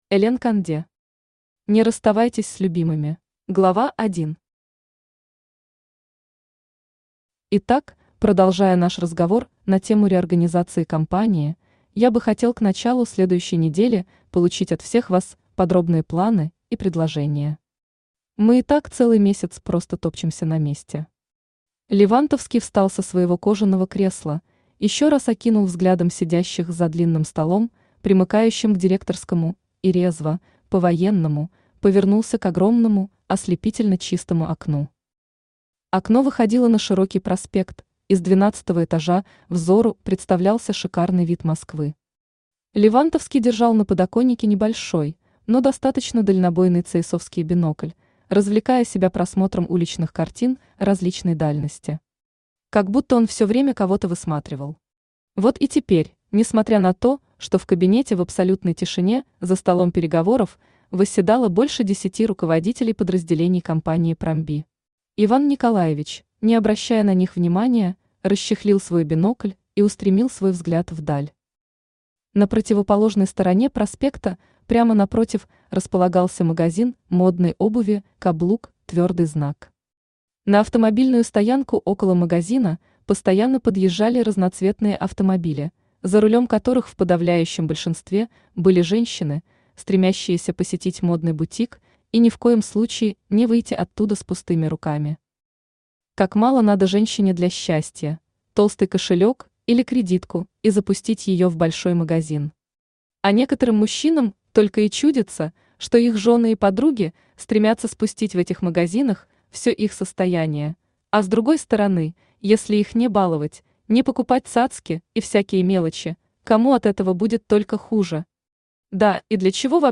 Аудиокнига Не расставайтесь с любимыми | Библиотека аудиокниг
Aудиокнига Не расставайтесь с любимыми Автор Элен Конде Читает аудиокнигу Авточтец ЛитРес.